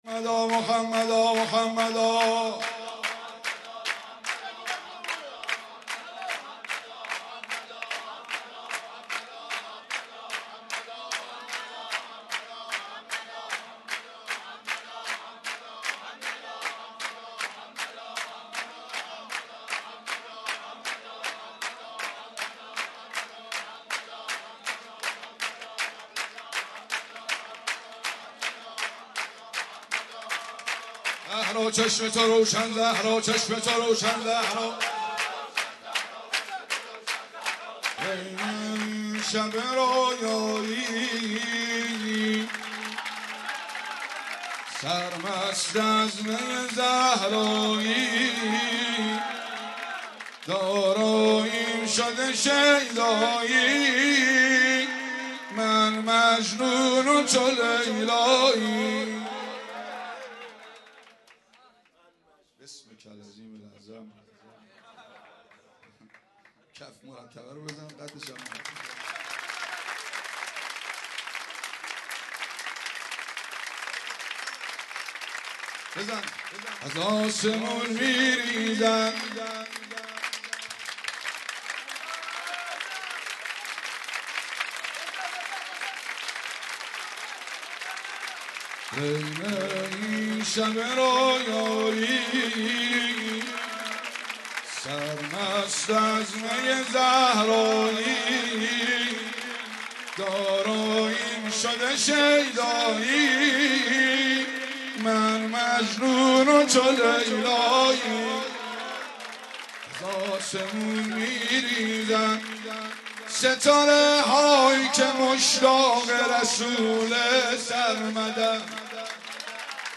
جشن میلاد حضرت محمد(ص)و امام صادق(ع)-فاطمیون قم